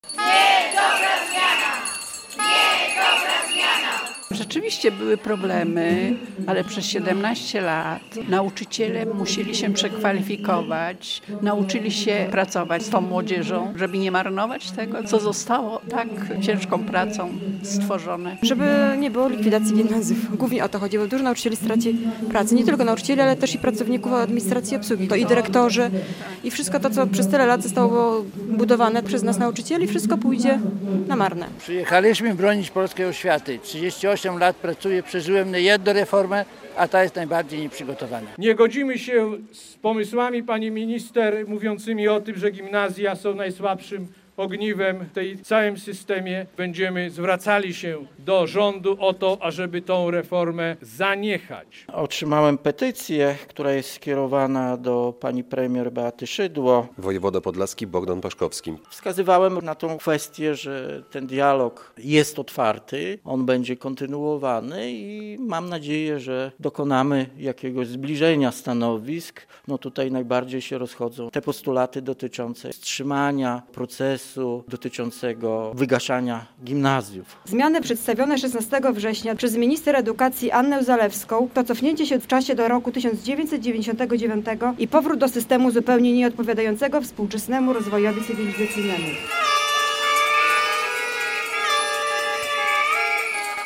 relacja
Podlascy działacze Związku Nauczycielstwa Polskiego protestowali przed Podlaskim Urzędem Wojewódzkim w Białymstoku.